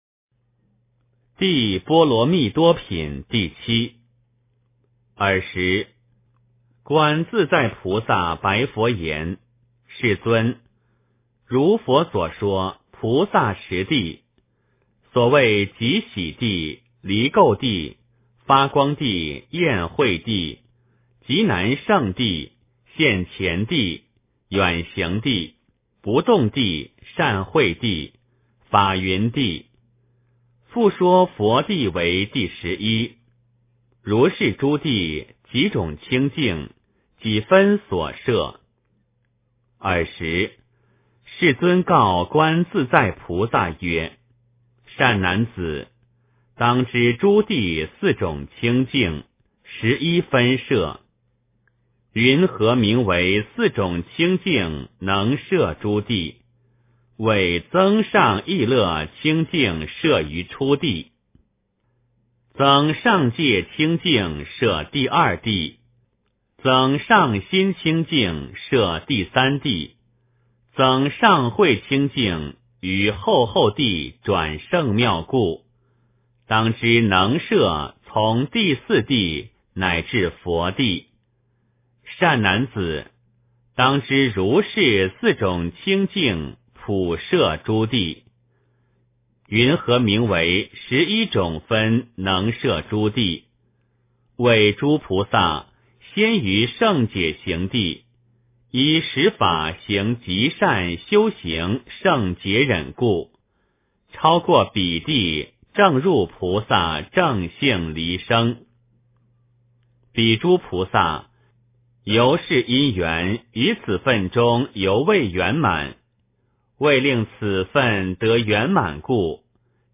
解深密经-7（念诵）